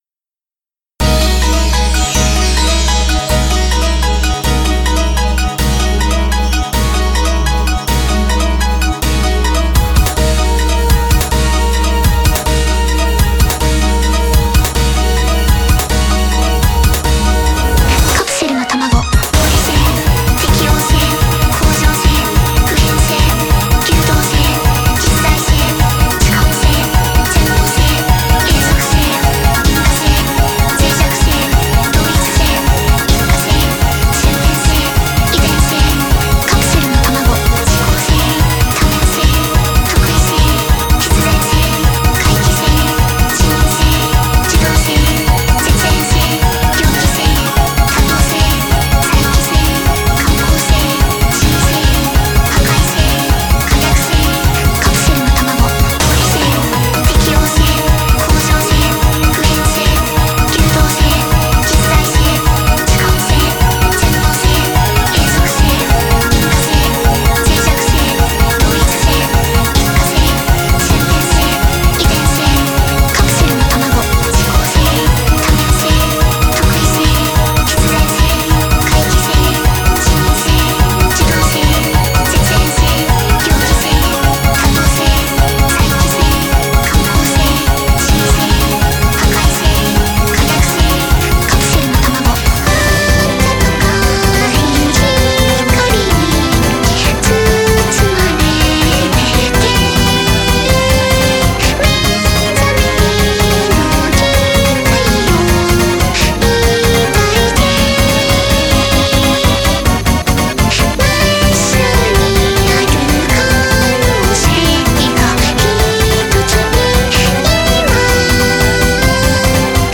Primeira metade em 11/16, segunda metade em 9/16.